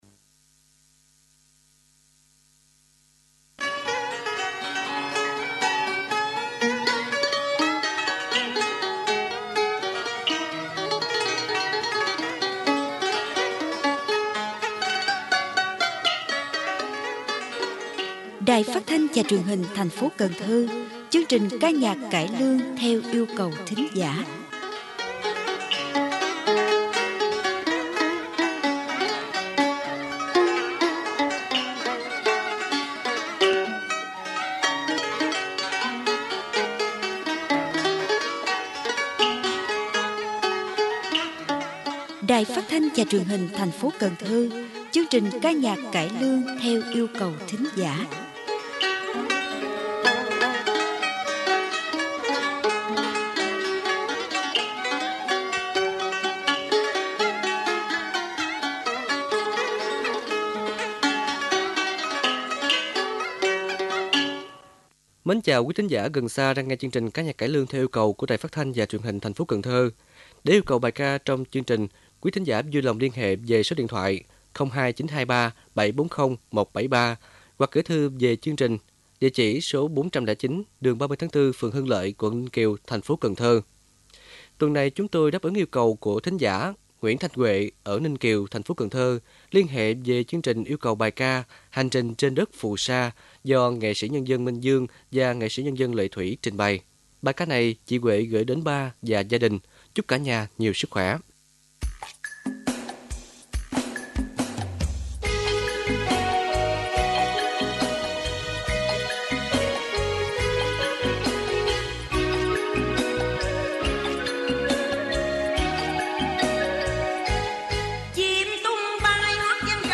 Mời quý thính giả nghe chương trình Ca nhạc cải lương theo yêu cầu thính giả của Đài Phát thanh và Truyền hình thành phố Cần Thơ.